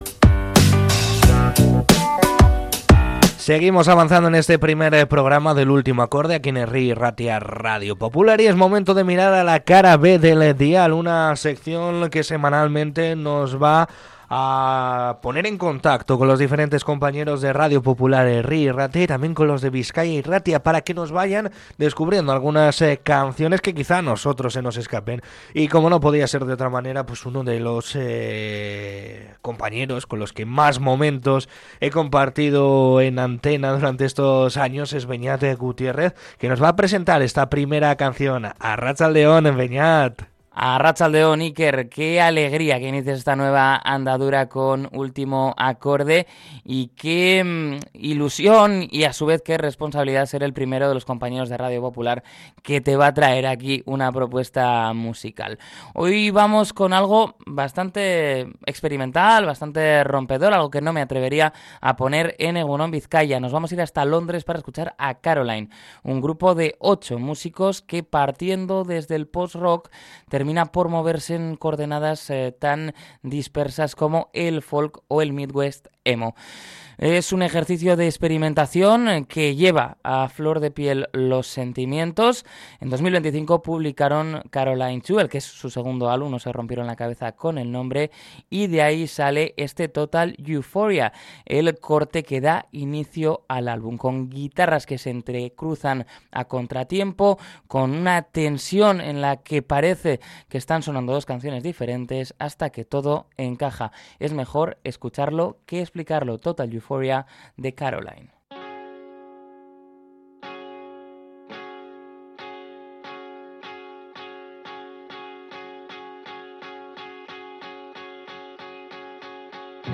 El post-rock londinense y el 'midwest emo' se dan la mano en la primera recomendación de nuestra nueva sección
En Total Euphoria, la banda juega con la percepción del oyente.